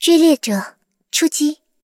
追猎者出击语音.OGG